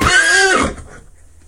PixelPerfectionCE/assets/minecraft/sounds/mob/horse/hit2.ogg at mc116